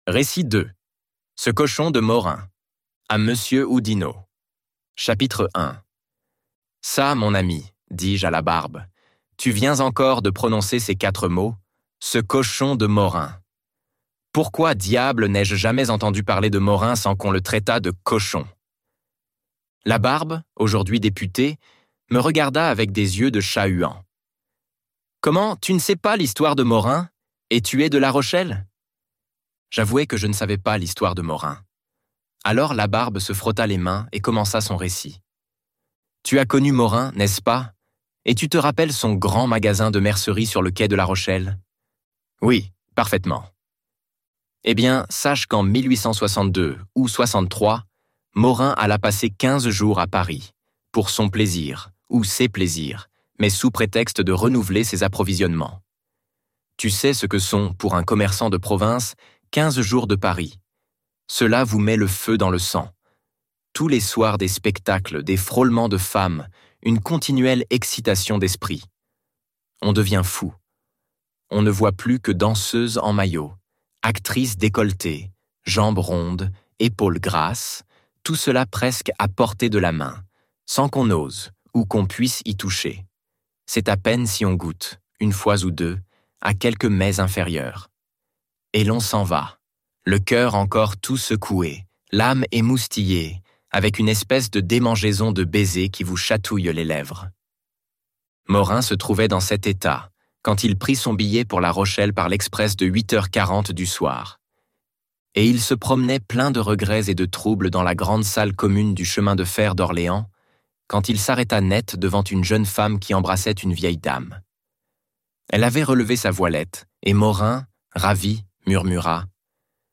Contes de la Becasse - Livre Audio